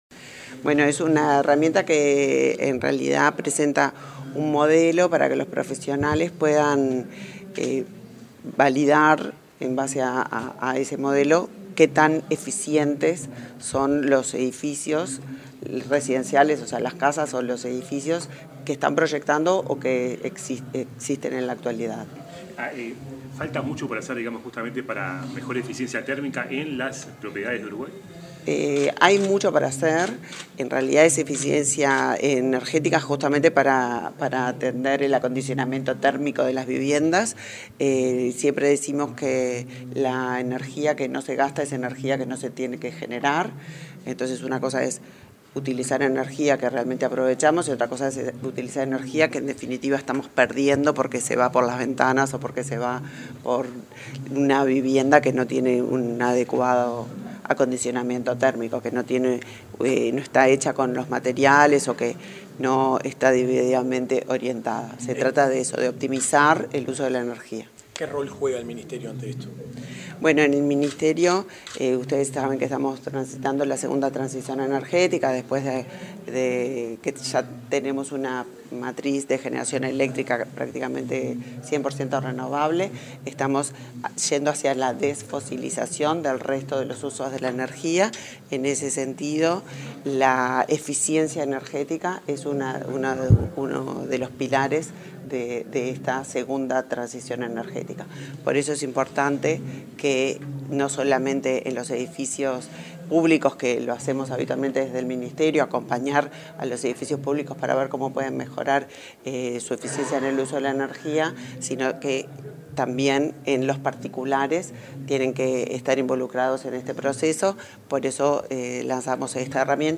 Declaraciones de la ministra de Industria, Energía y Minería, Elisa Facio
Declaraciones de la ministra de Industria, Energía y Minería, Elisa Facio 17/06/2024 Compartir Facebook X Copiar enlace WhatsApp LinkedIn Tras el lanzamiento del Modelo de Cálculo de Desempeño Energético para Viviendas, este 17 de junio, la ministra de Industria, Energía y Minería, Elisa Facio, realizó declaraciones a los medios de información.